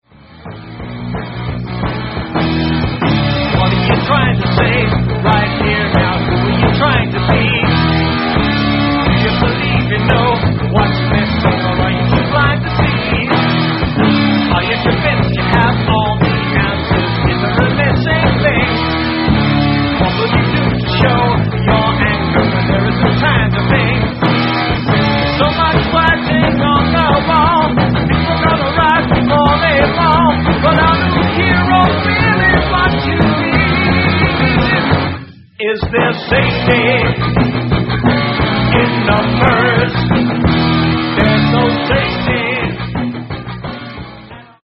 Writing Demos Live In London '82